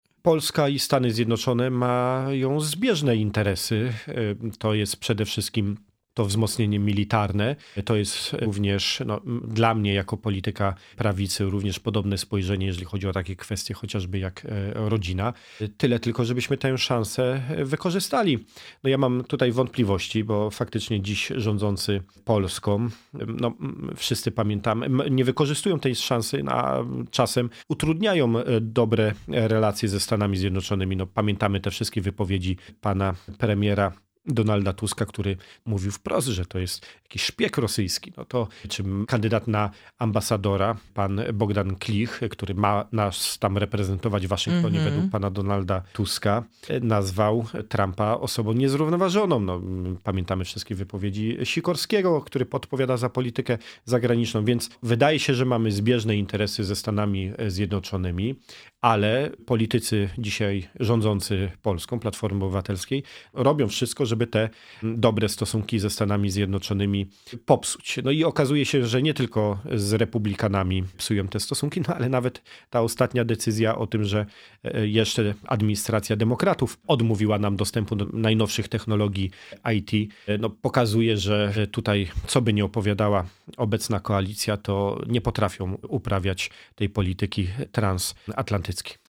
Te tematy poruszyliśmy z „Porannym Gościem” – posłem na Sejm Pawłem Hreniakiem, szefem struktur PiS w okręgu wrocławskim.
Dziś zaprzysiężenie Donalda Trumpa na 47. prezydenta Stanów Zjednoczonych. O nadziejach dla Polski związanych z jego prezydenturą mówi poseł na Sejm Paweł Hreniak, szef struktur PiS w okręgu wrocławskim.